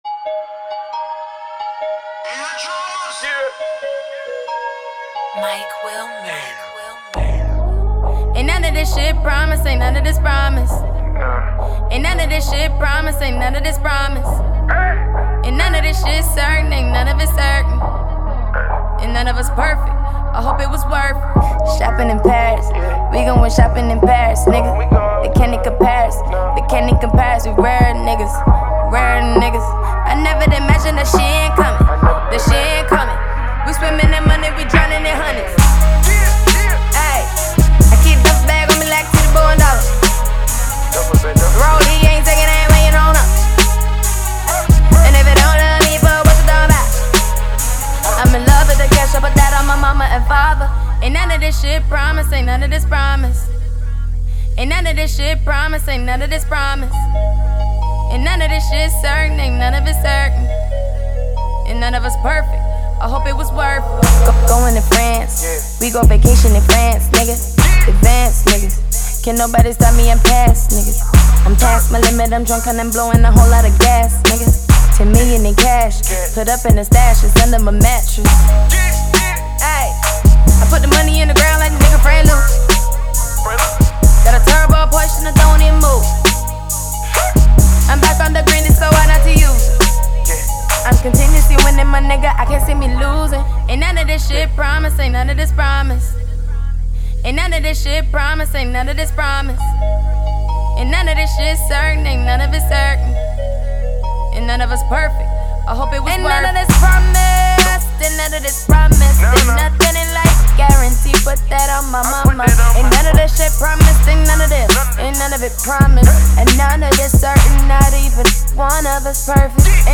but it’s a hype track nonetheless.